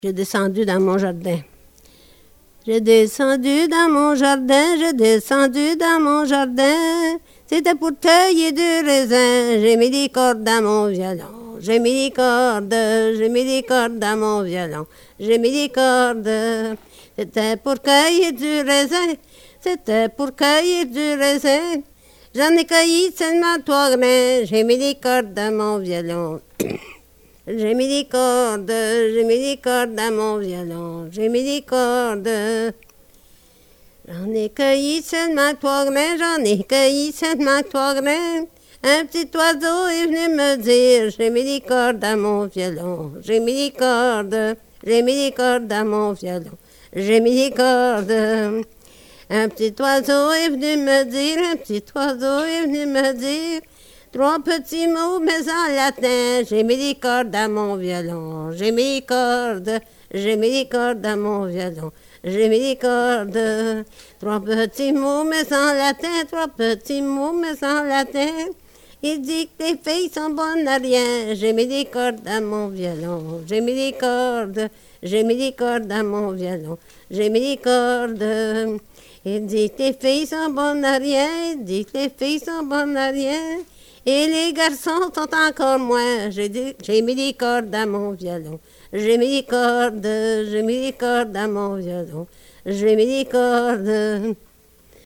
Folk Songs, French--New England
sound cassette (analog)